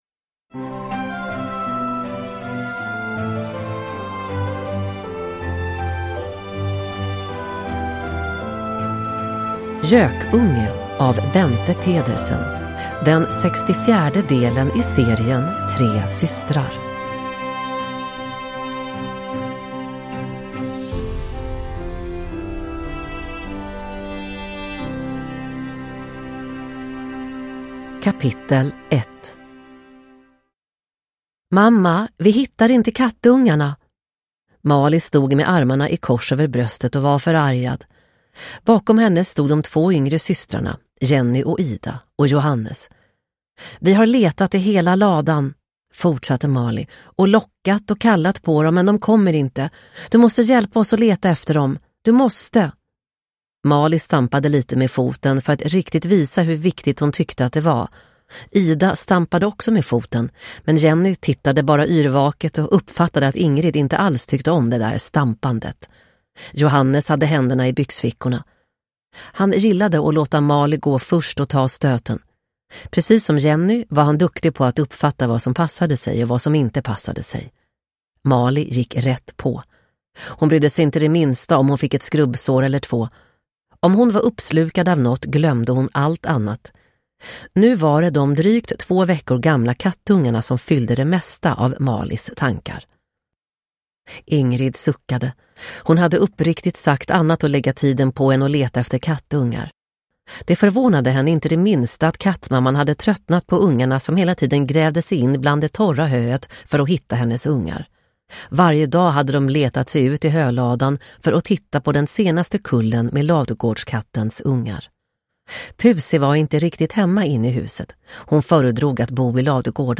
Gökunge – Ljudbok – Laddas ner